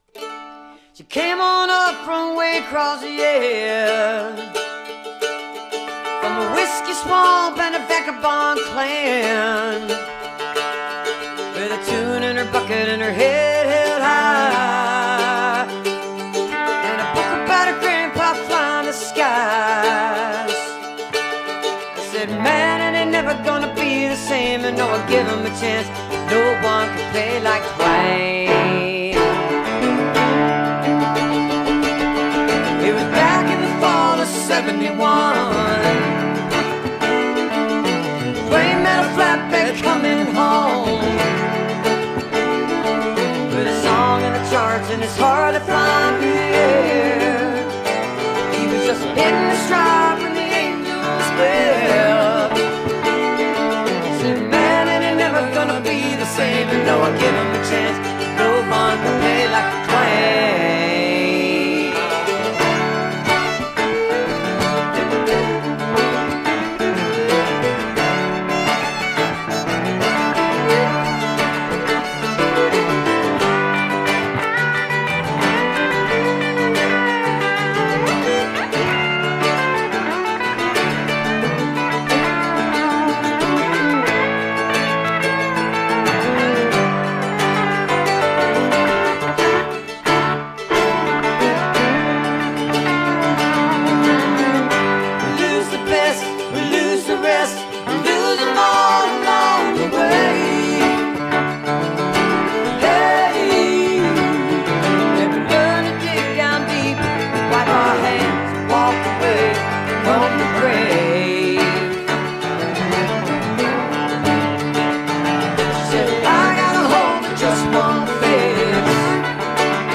(recorded from a webcast)